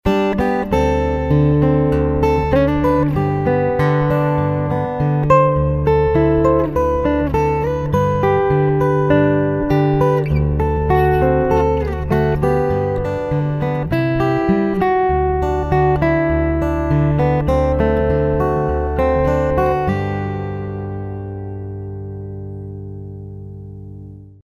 Compared to a decent microphone, preamp, and audio interface these recordings are fuzzy, noisy, and crude, but they do the job, and the price is definitely right.
Here’s one verse of an old Hawaiian song, “No Ke Ano Ahiahi,” recorded as a solo guitar instrumental using the the 1/8″ mini plug adapter and Audacity.
And here’s the same track with some reverb added.
Obviously these are not “major label quality” recordings by a long shot. The direct output from a pickup is not very close to the natural acoustic sound of your guitar.